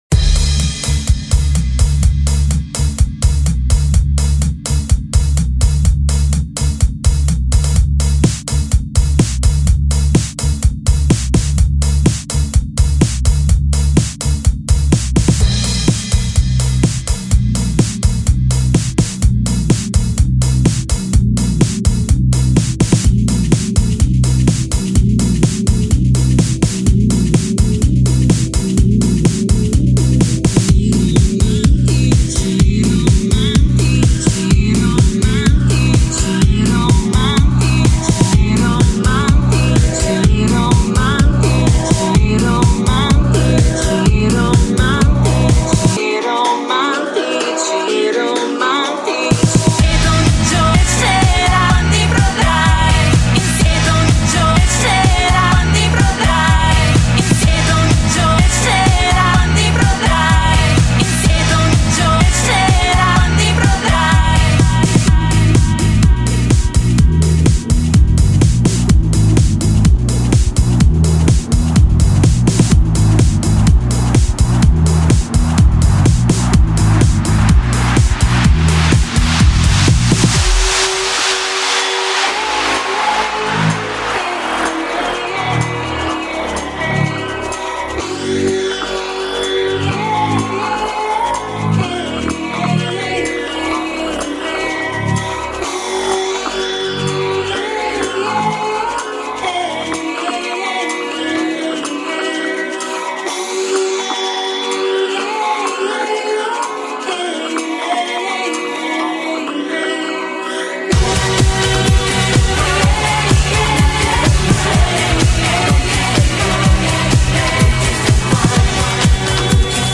What a fun track to remix, and so catchy too.